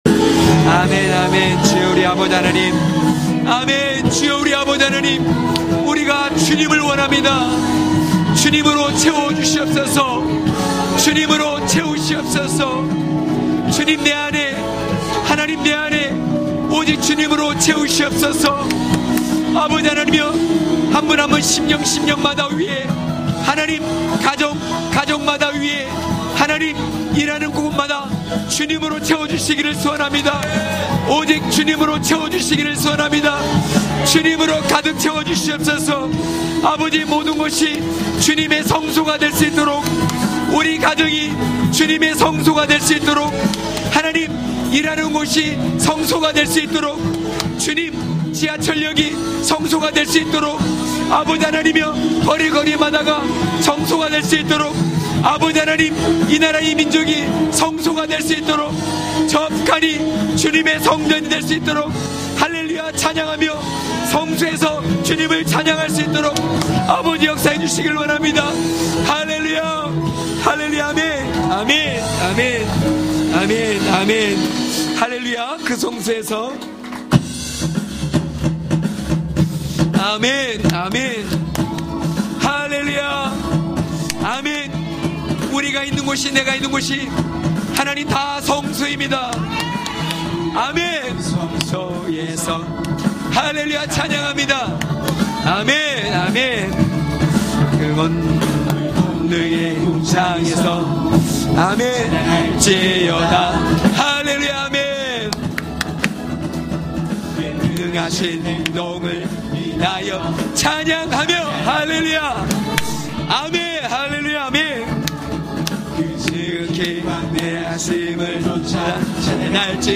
강해설교 - 18.예배의 회복이 말씀의 회복이다!!(느10장34~39절).mp3